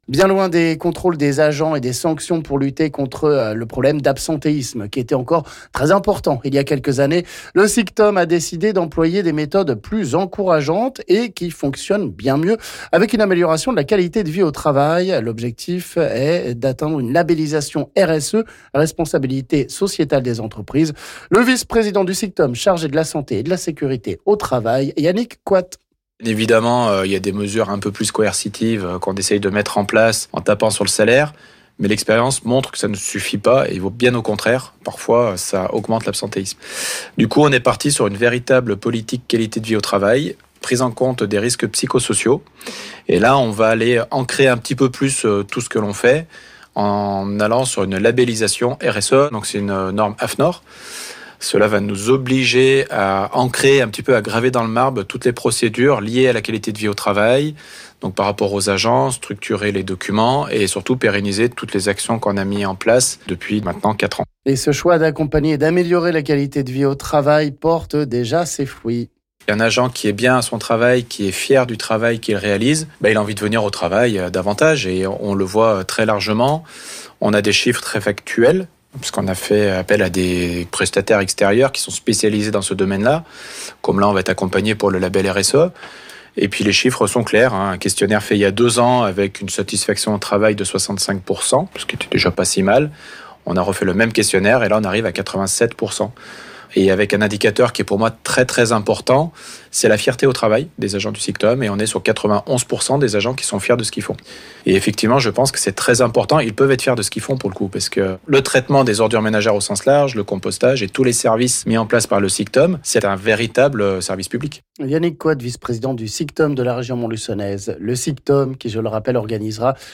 On en parle ici avec le vice-président du SICTOM Yannick Coite...